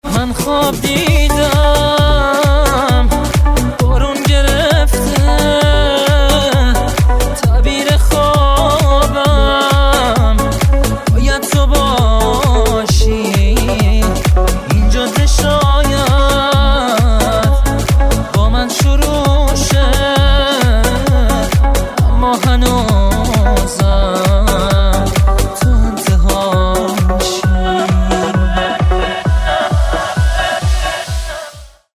زنگ موبایل
رینگتون با انرژی و احساسی باکلام